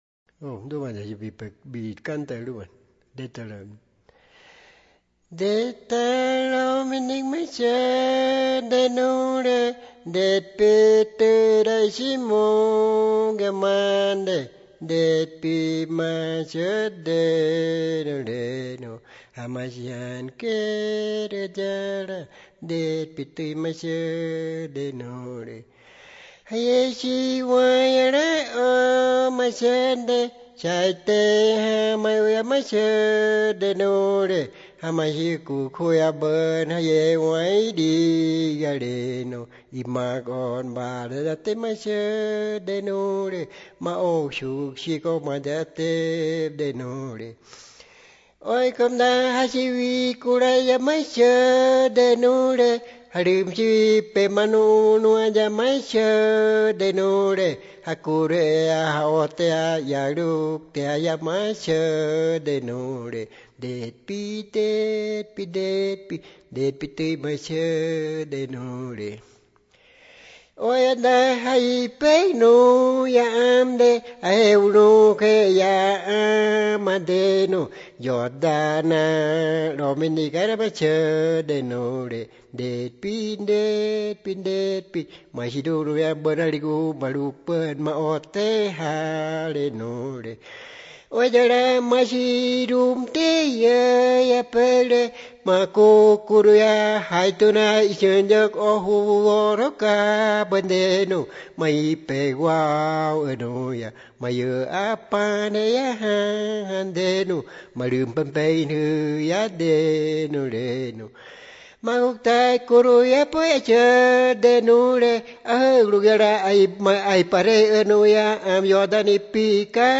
Piedra Alta, Medio Inírida, Guainía (Colombia)
La grabación, transcripción y traducción de la canción se hizo entre marzo y abril de 2001 en Piedra Alta; una posterior grabación en estudio se realizó en Bogotá en 2004
Song of the rivalry between paca and tiger; the paca challenges the tiger that she will be far away from him eating at night and will flee from 'you'; then the tiger tells her that he will make her come out of her burrow and even if she jumps into the river he will catch her. The recording, transcription, and translation of the song took place between March and April 2001 in Piedra Alta; a subsequent studio recording was made in Bogotá in 2004.